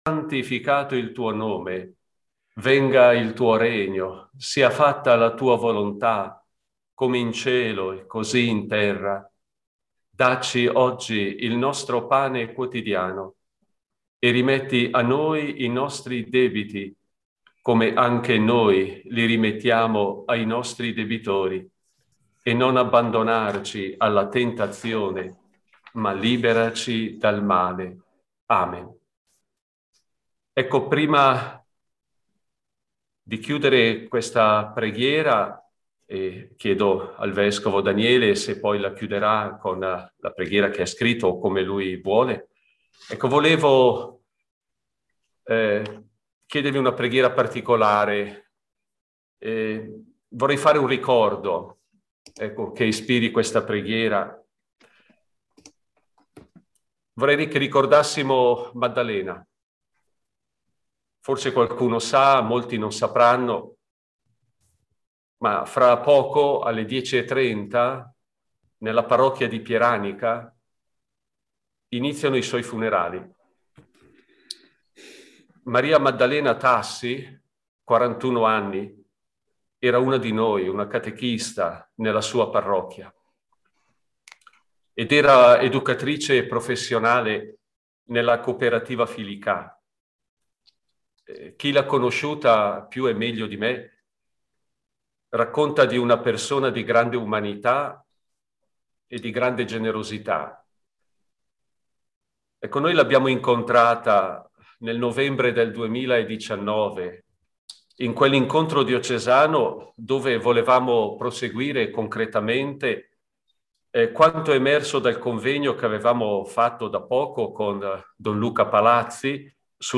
Convegno diocesano catechisti sabato 17 aprile 2021. Materiali e Video
La relazione inizia al minuto 08:45.